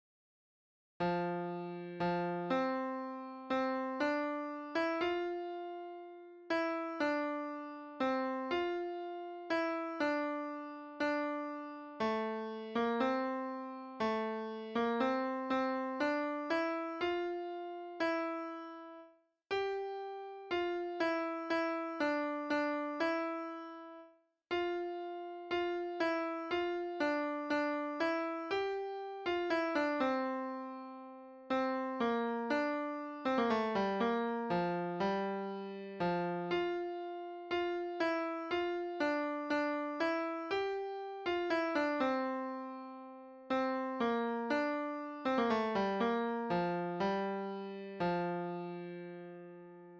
vierstemmig gemengd zangkoor
In bijlagen de gezongen versie van het lied, ook erbij de verschillende stemmen